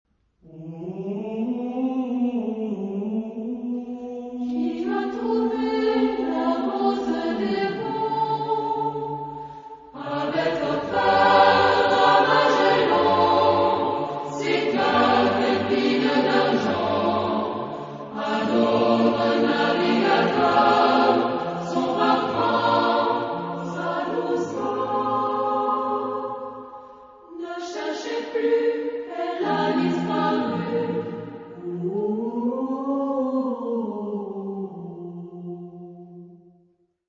Epoque: 20th century
Genre-Style-Form: Secular ; Partsong
Mood of the piece: bouncing
Type of Choir: SATB  (4 mixed voices )
Tonality: free tonality